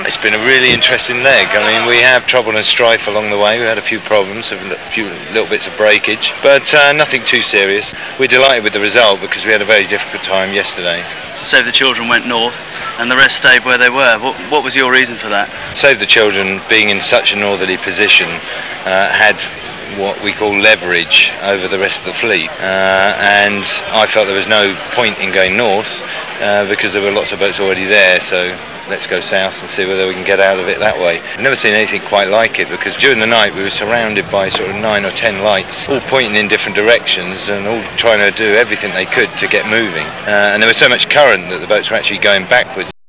The following clips were recorded during the race.
Mike Golding, Skipper, Group 4